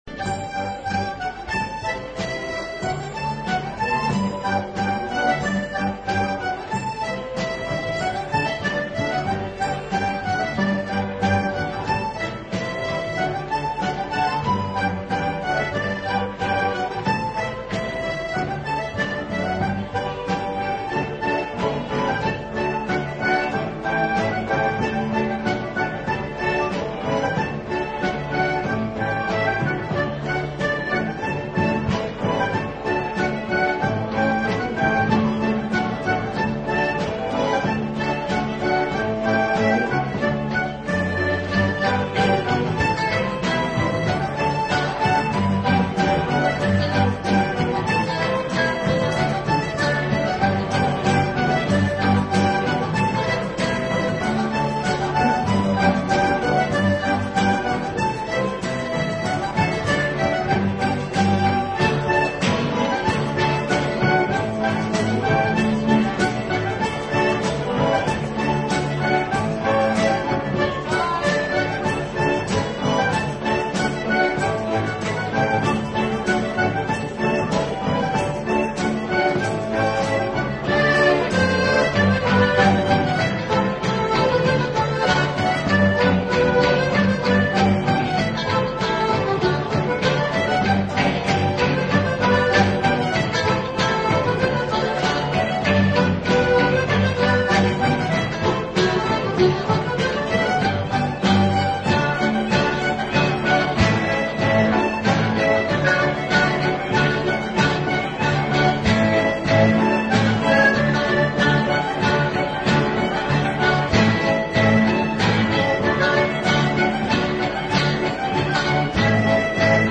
Long before The Hosepipe Band and Bass Instincts, there was The Hooligan Band, an unwieldy 11-piece ceilidh band which crept on the Essex dance scene in 1979 when we played for Castle Hedingham Folk Club Christmas Ceilidh.
Here are few typically raucous live recordings by a band ahead of its time.